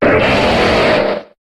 Cri de Jungko dans Pokémon HOME.